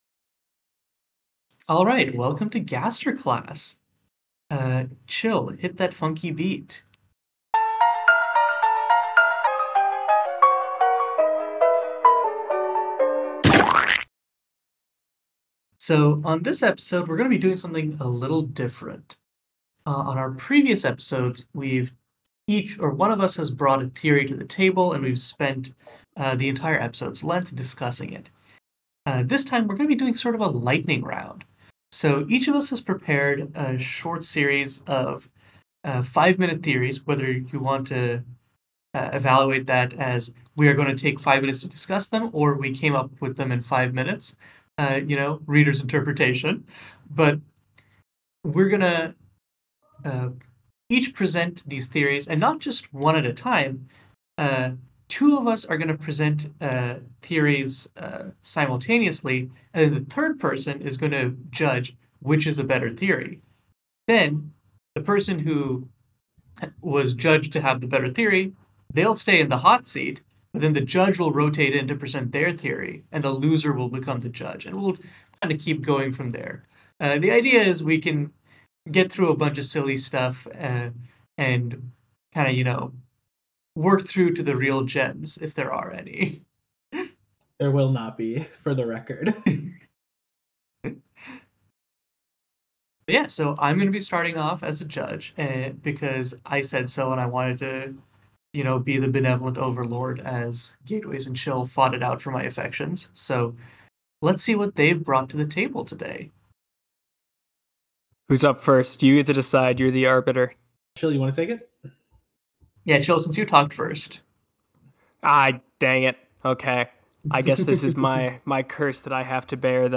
It's a speed-theory game show!